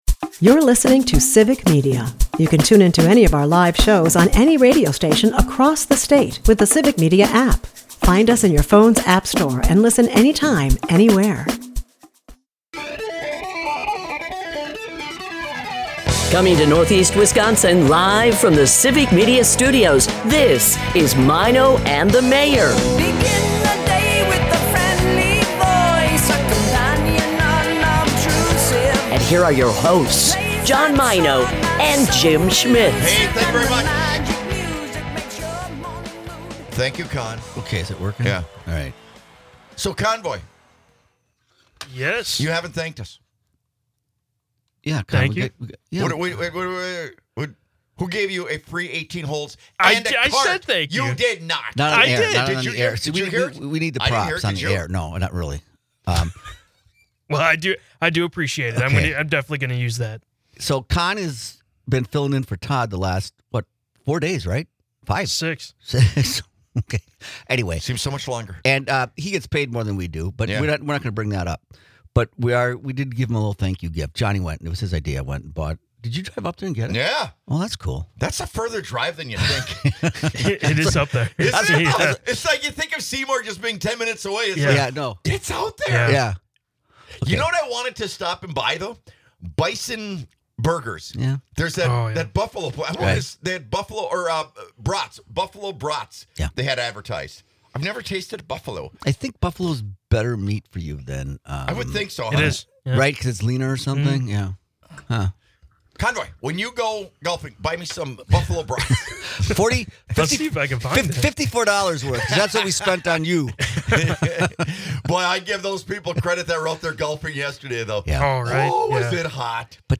Broadcasting live from Northeast Wisconsin